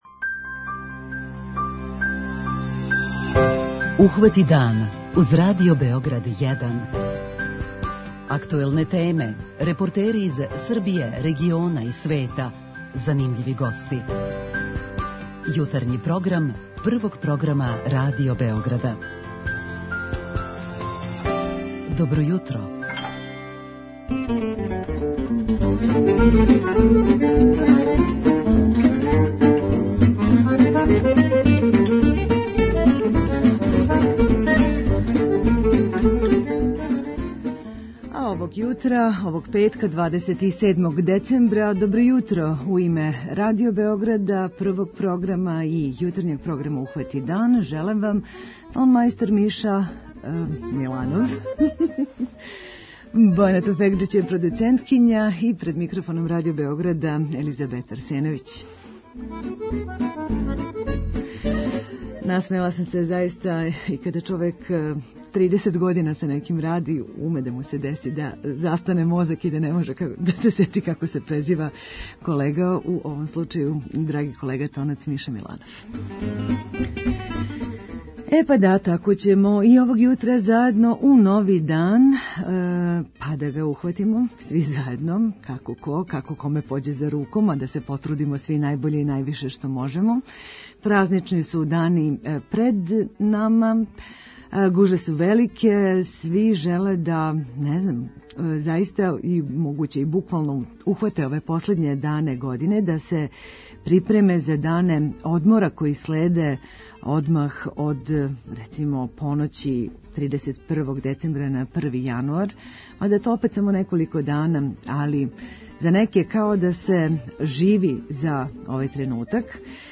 Да ли су кредити 'крајња мера' којој прибегавамо без обзира на услове под којима нам их банке нуде? То је питање овога јутра за вас, у конктакт-делу нашег програма, а повод је најава скраћивања рокова за отплату неких кредита - на првом месту готовинских - од почетка Нове године.